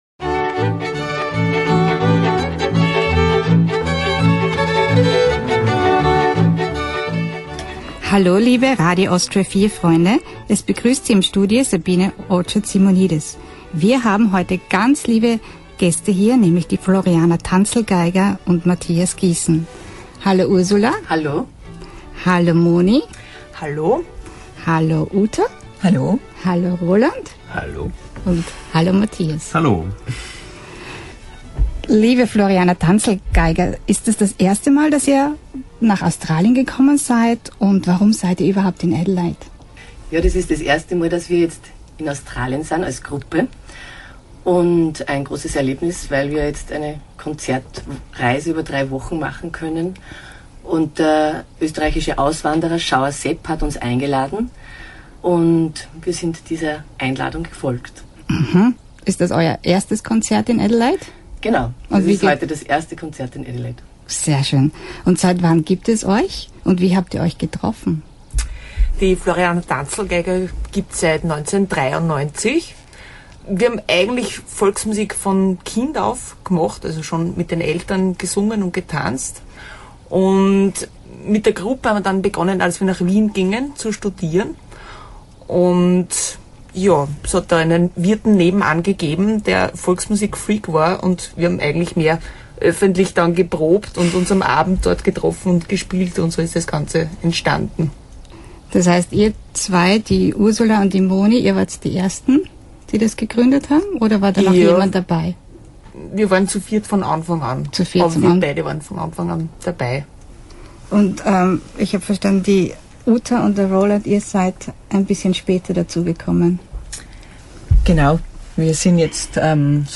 Radio Austria 4 Interview